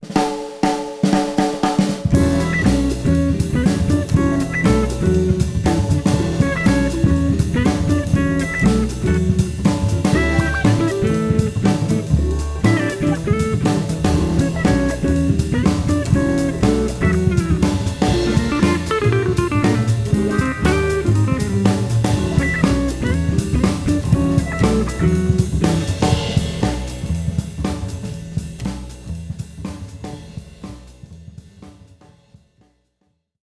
120FNKDEMO.wav